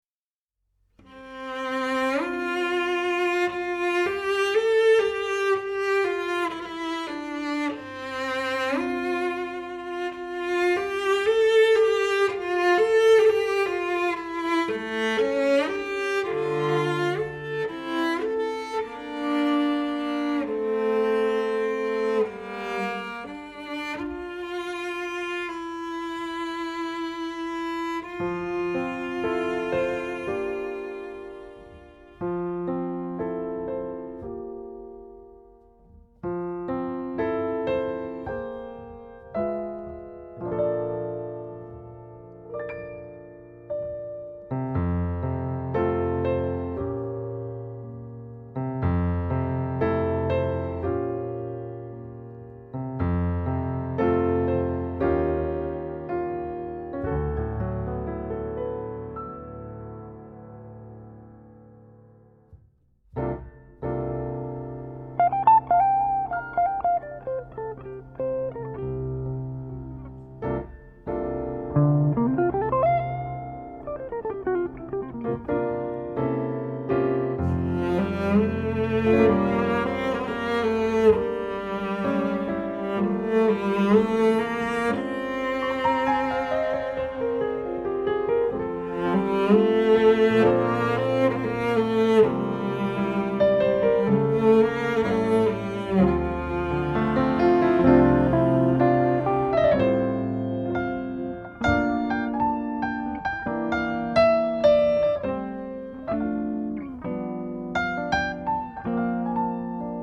★ 匯集多種音樂元素，中國優秀爵士音樂家鼎力相助，再現正宗古巴爵士樂風音樂！
烘托爵士現場的微妙氛圍。